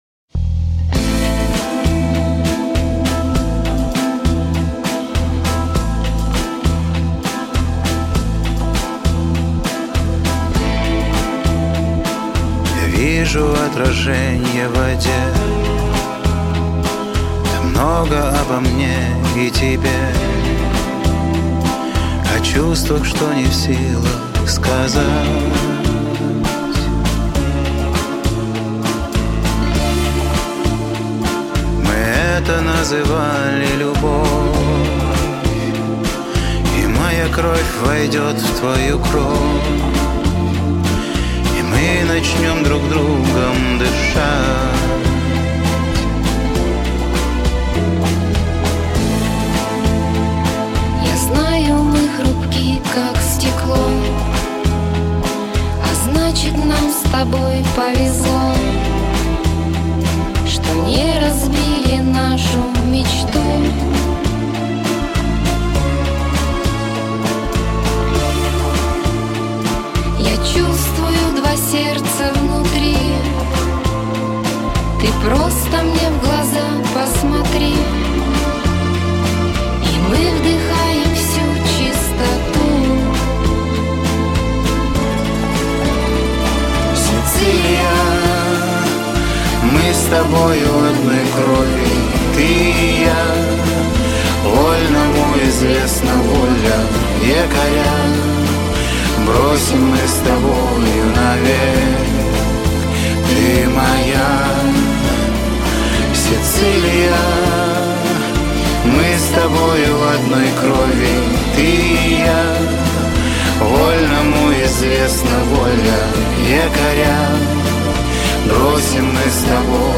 Такая трогательная песня...